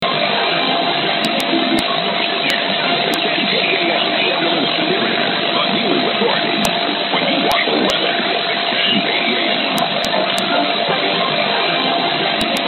A good recording of AM 10-60 and a possible KDAL id on 610.